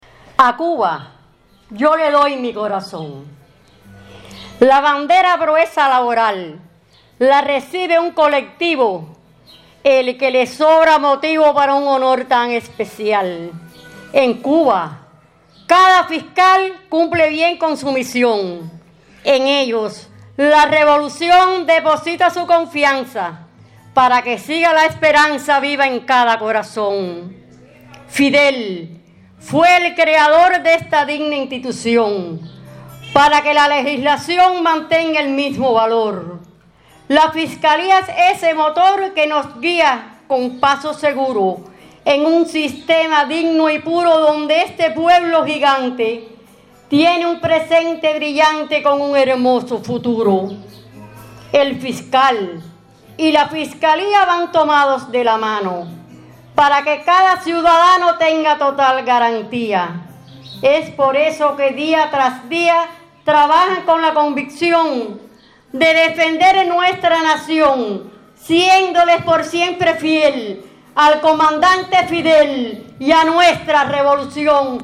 El Museo municipal Juan Gualberto Gómez constituyó el lugar idóneo para la actividad.
declamó décimas de su autoría que transmitieron la alegría por el recibimiento de la bandera y el compromiso para que continúen trabajando en pos del bienestar de la sociedad.